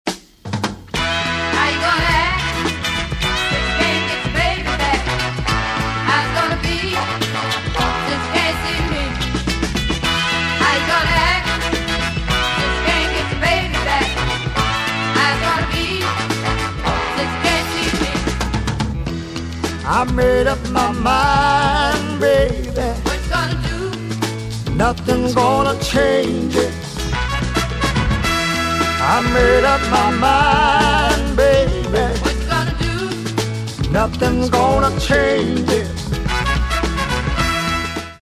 ギター、ヴォーカル
オルガン、ヴォーカル、フロント・マン
ドラムス